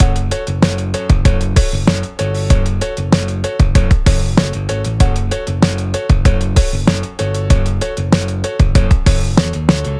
C:それを簡単にコピーした音楽
Cの方はまだまだリズムが良くなくて、ずいぶん気持ちよさが減ってしまいました。